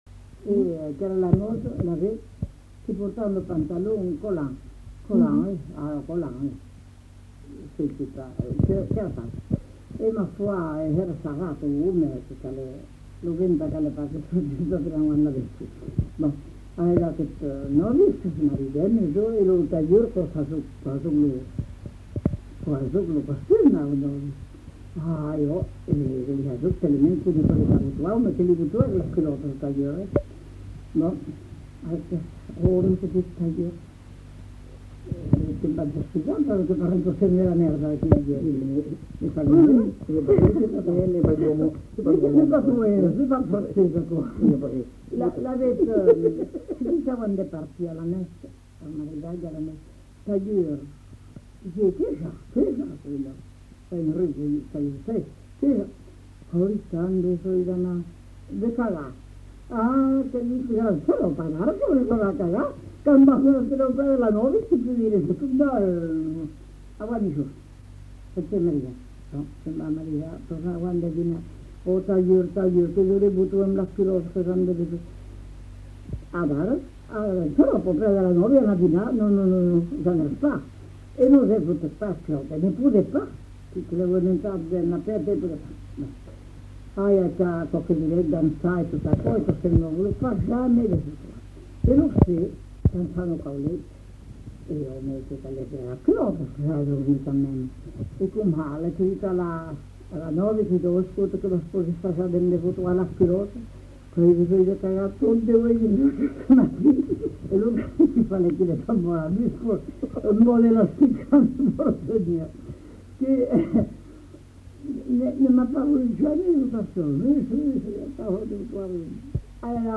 Aire culturelle : Marsan
Genre : conte-légende-récit
Effectif : 1
Type de voix : voix de femme
Production du son : parlé